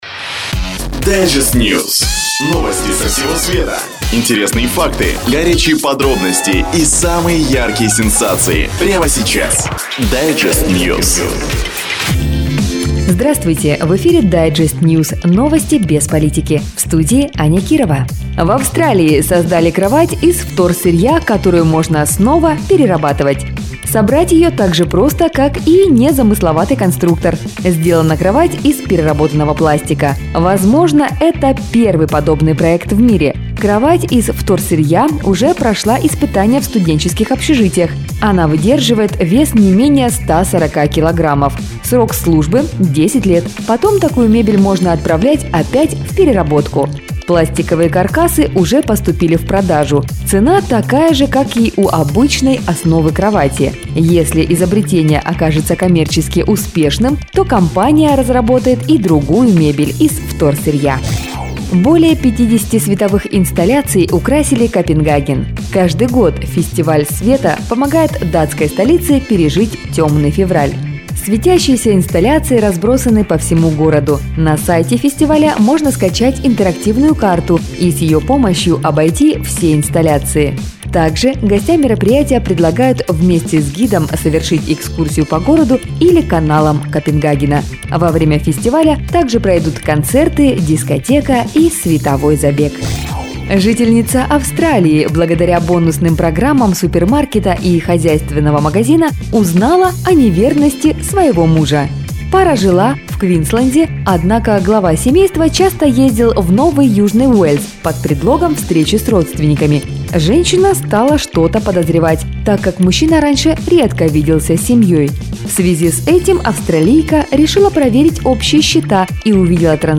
Digest-news – это новости со всего света БЕЗ ПОЛИТИКИ! Интересные факты, исследования учёных, горячие подробности и самые яркие сенсации.